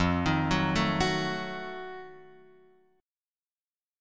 F7b9 chord